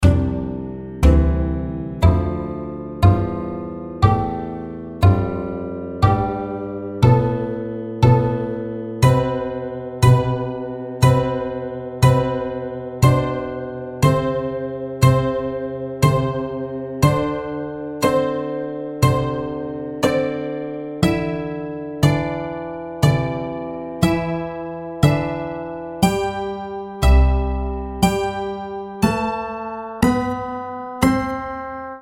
Mapeamento: variação da área de floresta para frequência Programa: Twotones 2009: piano 2012: doublebass 2015: mandolin 2018: electric guitar